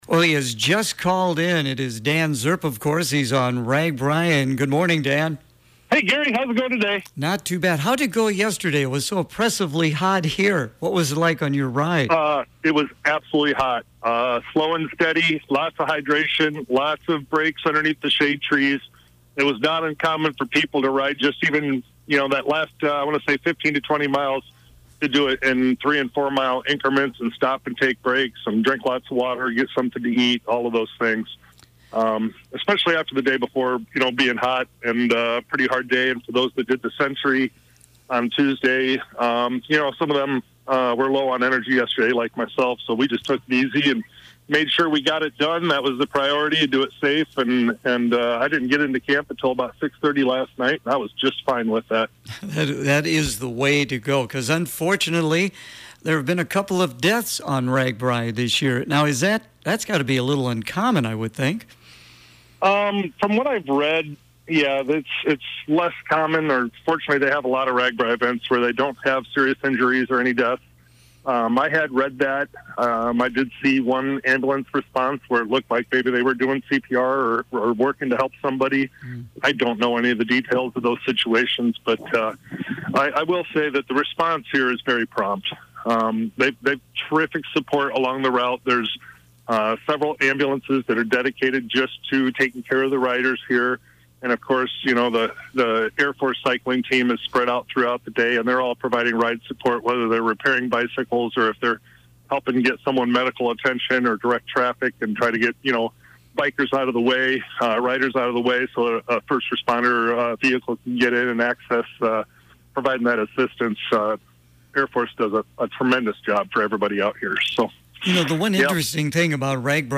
Clinton County Supervisor Dan Srp is once again riding on Ragbrai this year and is keeping listeners informed about this unique Iowa event.
Ragbrai Report 07-24-25